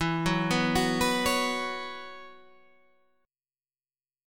Bsus2/E chord